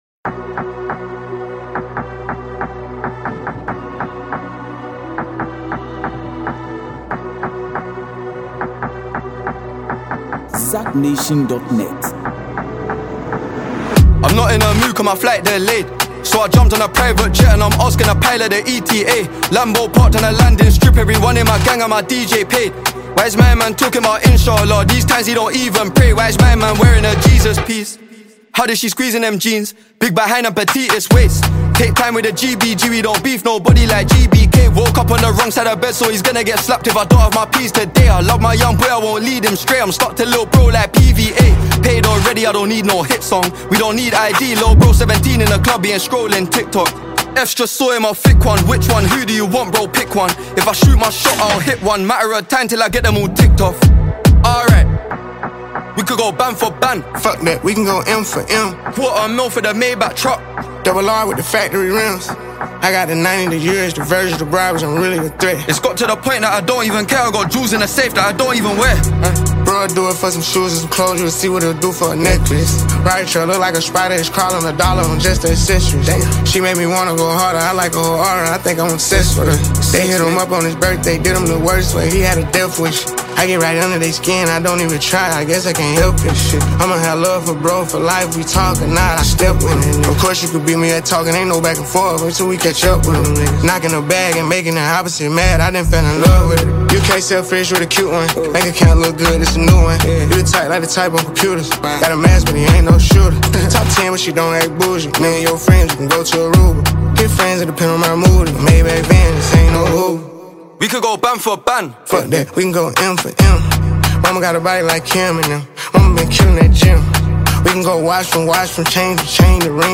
laid-back flow
rapid-fire rhymes